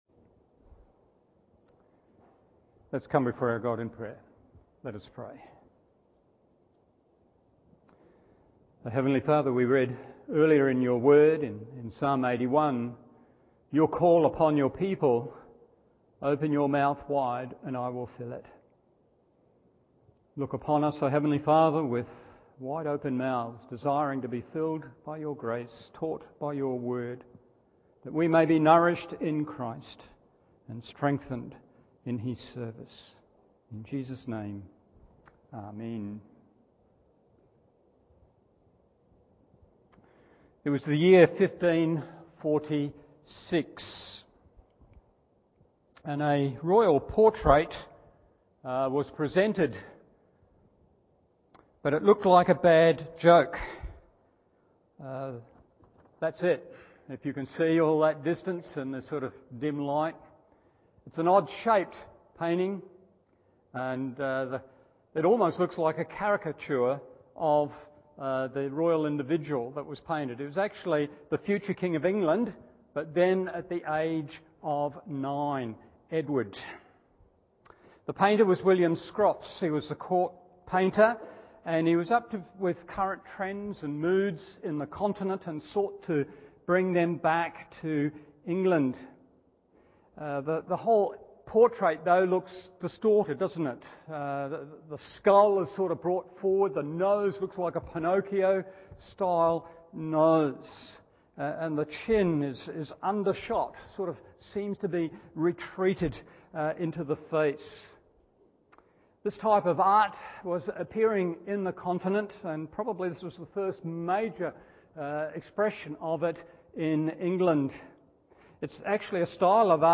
Evening Service 1 Thessalonians 1:9-10 1. He is the True God 2. He is to be Served 3. He is to be Trusted…